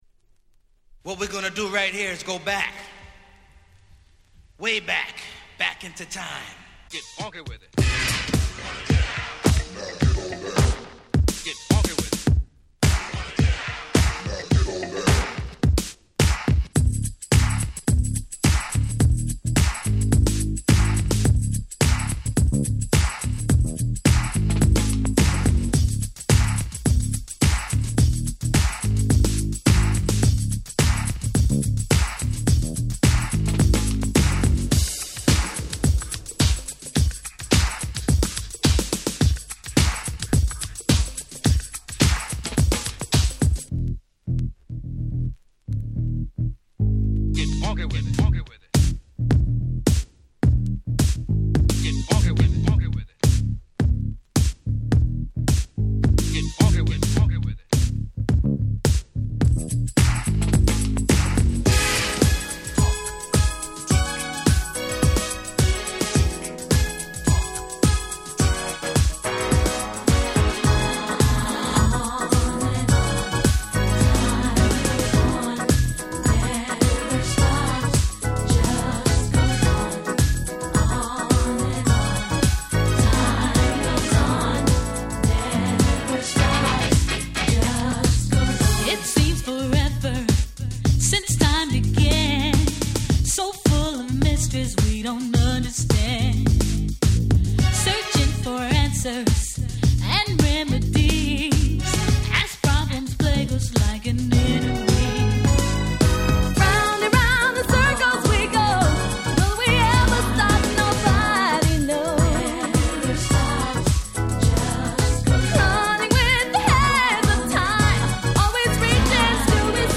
91' Remix EP !!